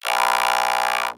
robotscream_3.ogg